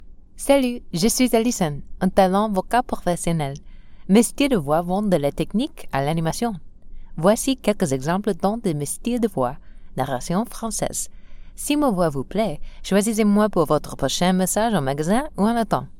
Female Voice Over, Dan Wachs Talent Agency.
Conversational, Real, Warm.
Narration - French